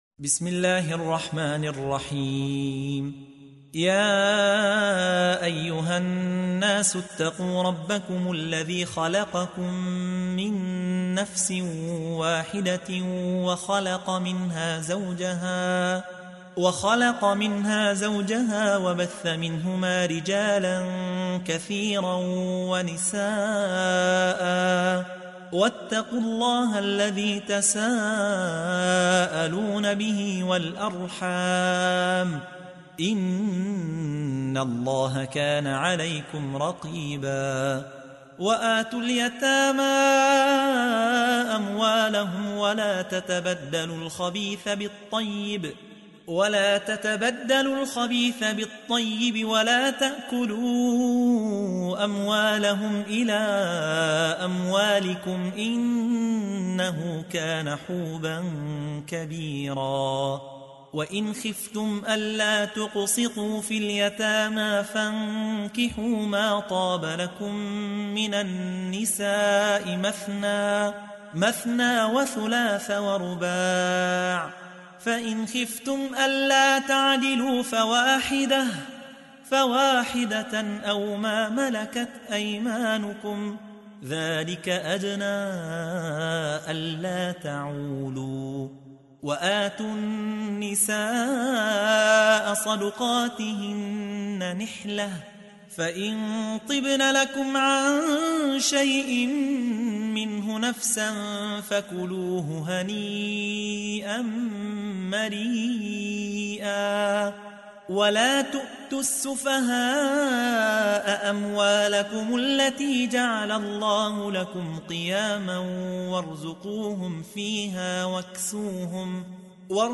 تحميل : 4. سورة النساء / القارئ يحيى حوا / القرآن الكريم / موقع يا حسين